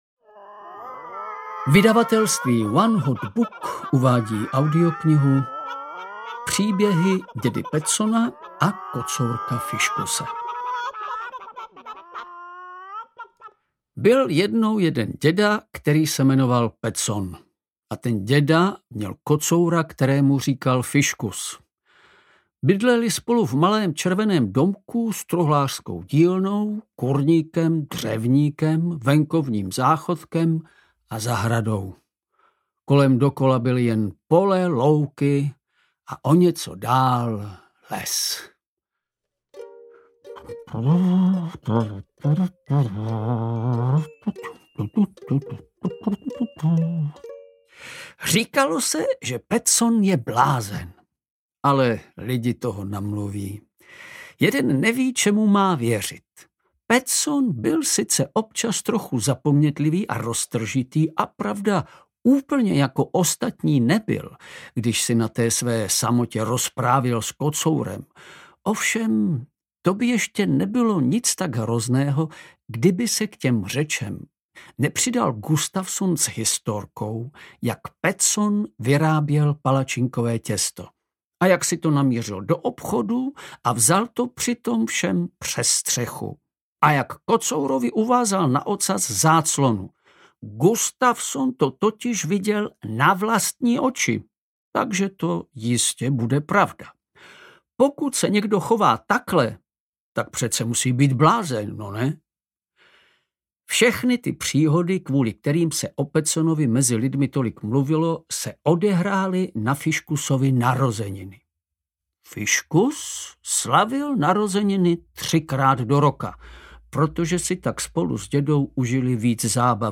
Audio knihaDobrodružství kocoura Fiškuse a dědy Pettsona 2
Ukázka z knihy
• InterpretVladimír Javorský